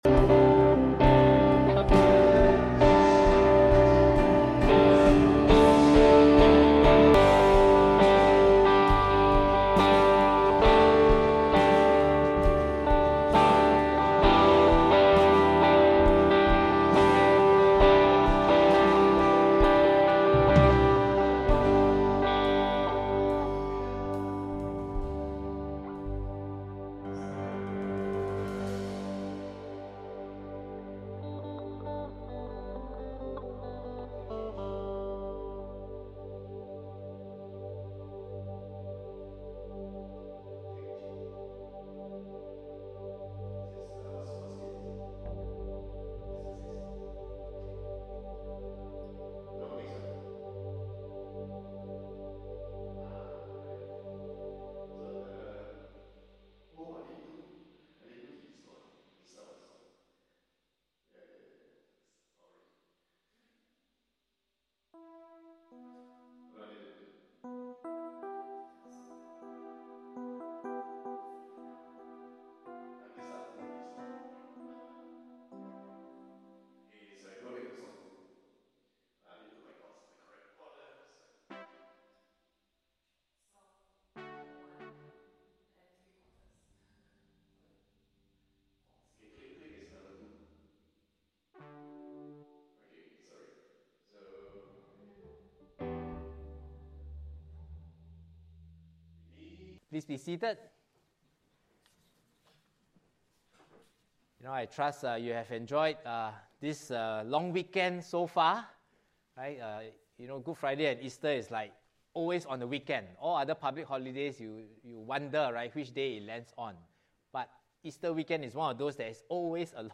HC Service